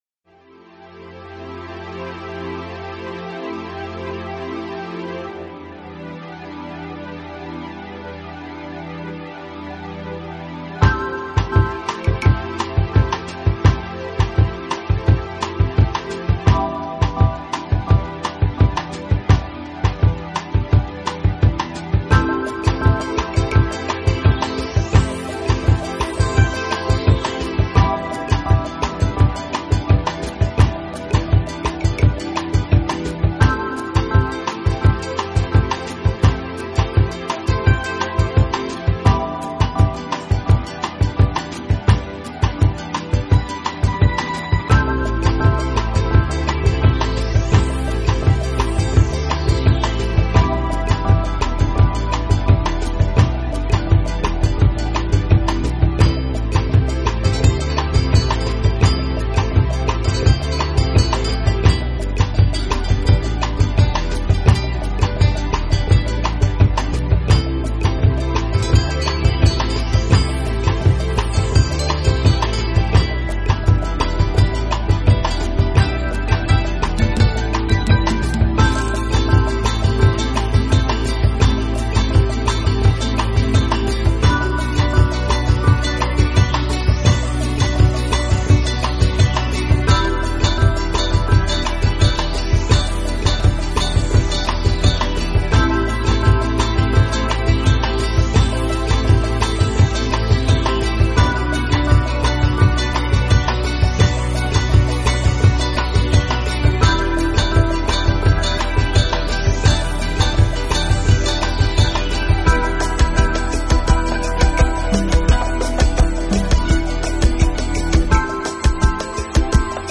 音乐给人带来温馨平和的气氛，也让现代忙碌的都会人带来一种舒缓、放鬆的效果，
旋律依旧是那闻名于世的佛拉明戈吉他，以及偶尔出现的佛拉明戈所独有的和声部